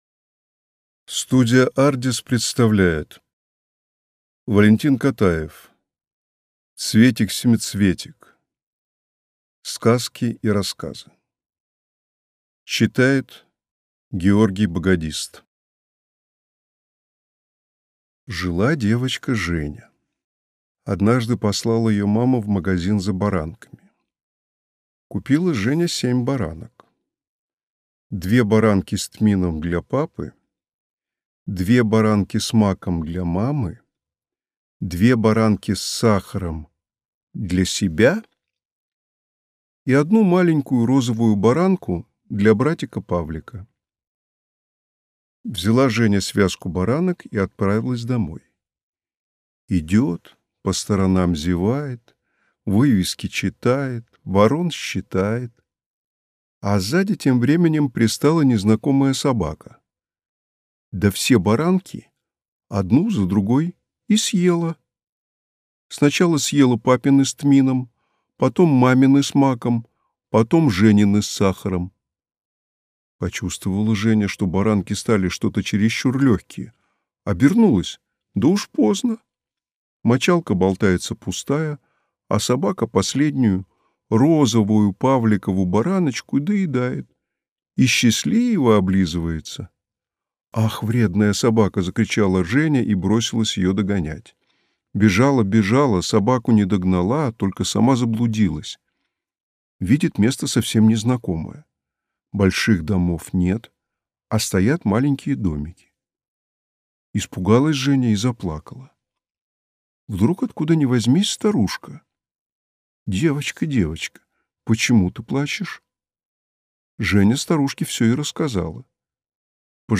Аудиокнига Сказки и рассказы | Библиотека аудиокниг